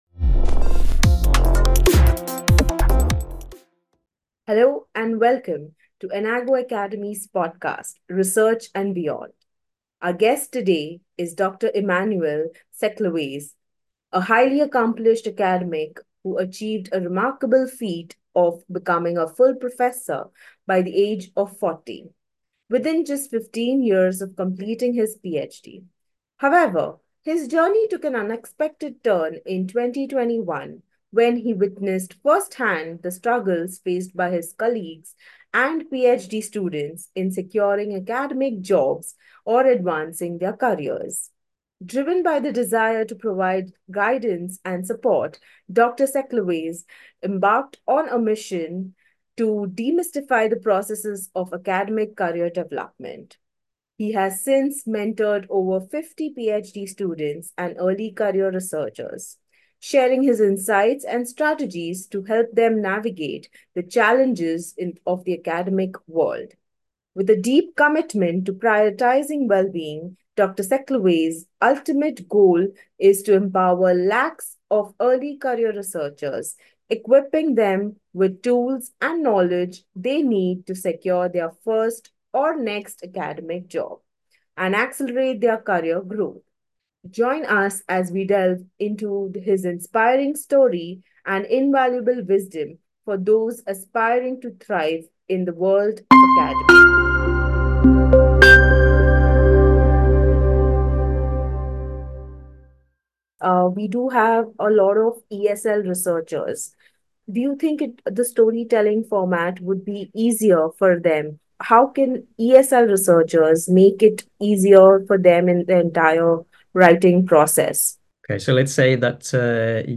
Dive into the conversation now!